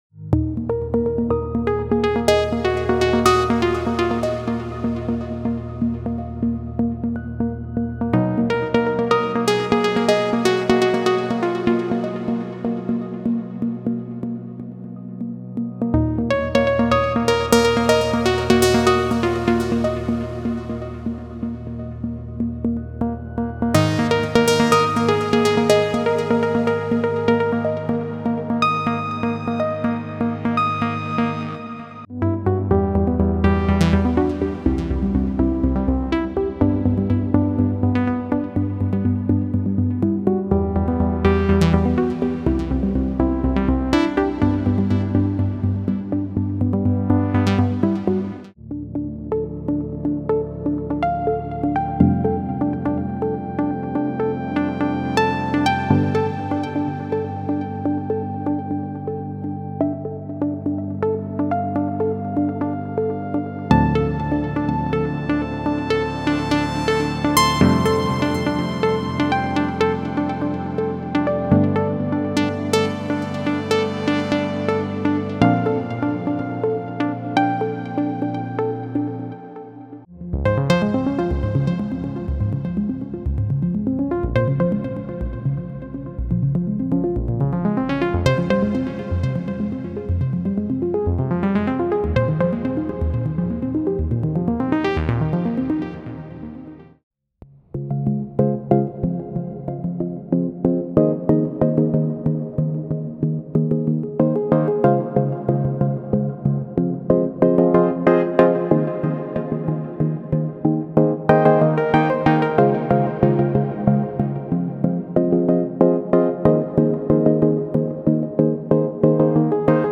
探索美妙的旋律主题、梦幻般的拨弦、不断演变的和弦进行以及节奏丰富的音型，激发您的创作灵感，打造完整而引人入胜的作品。
多种音乐风格 • 激发完整音乐创作灵感的旋律主题和拨弦音色